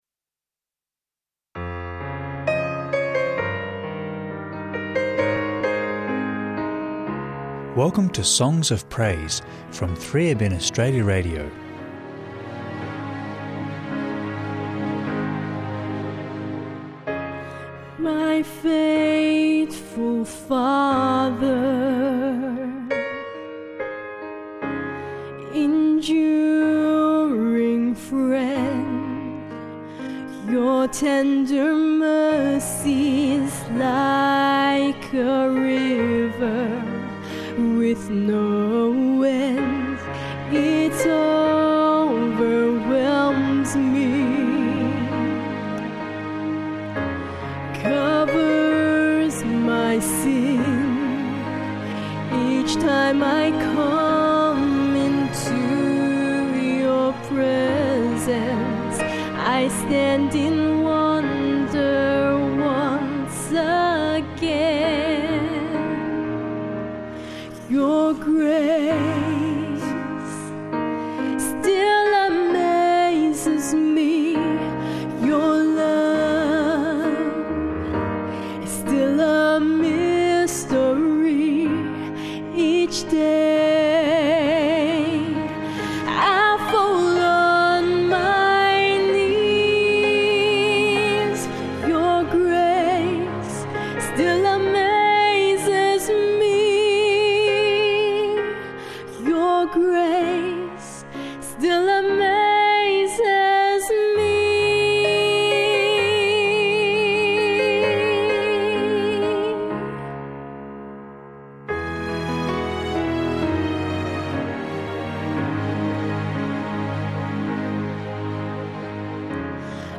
uplifting Christian hymns and worship music